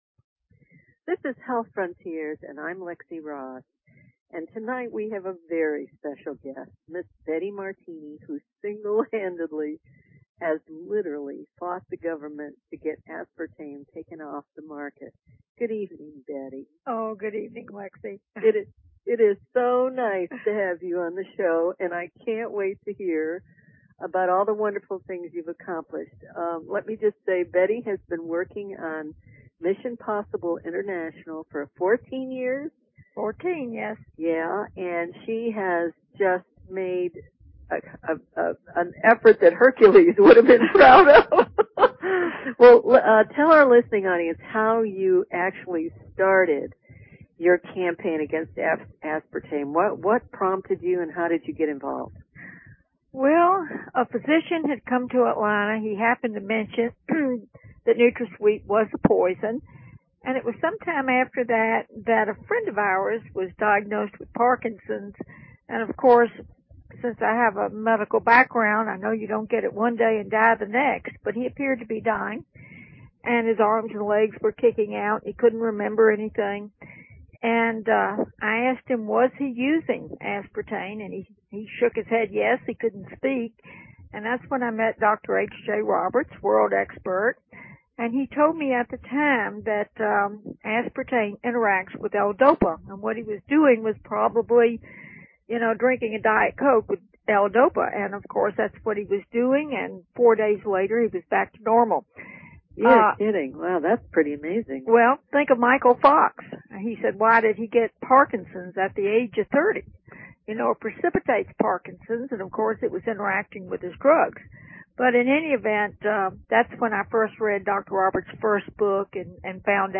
Talk Show Episode, Audio Podcast, Health_Frontiers and Courtesy of BBS Radio on , show guests , about , categorized as
Interview